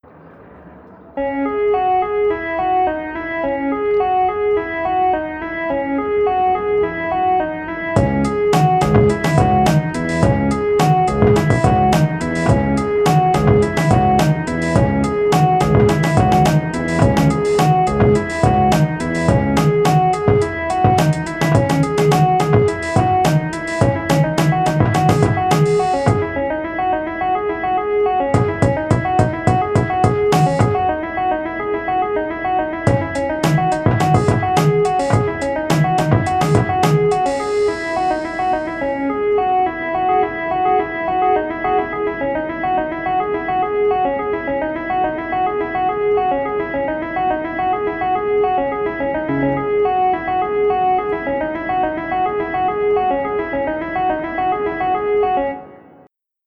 它需要一些技巧，并且需要付出不小的努力来保持乐器的强烈，均匀的音调。
我们通过移除后面板来录制 Estey 风琴，后面板发出更直接的声音，并最大限度地减少了相当大的踏板噪音。我们加入了单独的踏板噪音，可以混合（或不混合）以实现真实感。